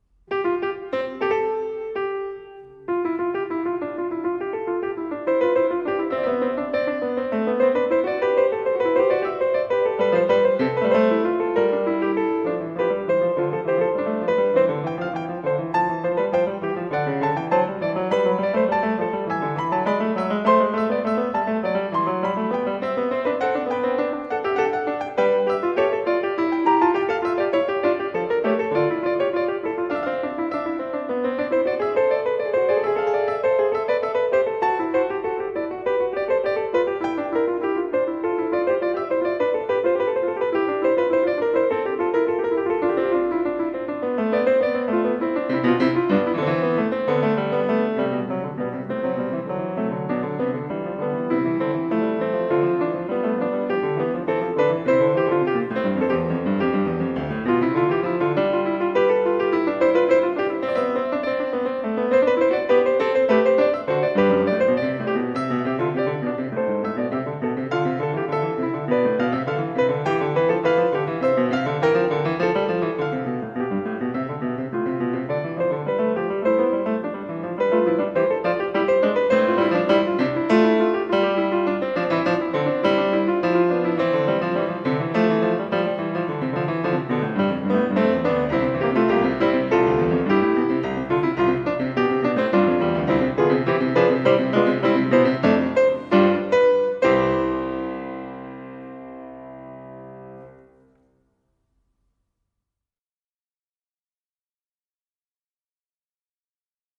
Piano version
Piano  (View more Intermediate Piano Music)
Classical (View more Classical Piano Music)
18th century    baroque